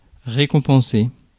Ääntäminen
Ääntäminen US
IPA : /ˌɹɛkəmˈpɛns/